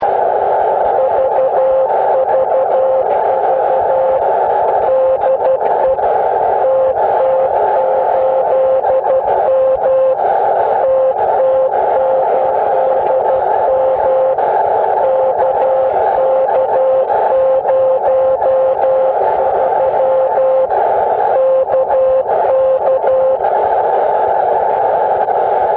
TT8M Hear His Signal in Rome!.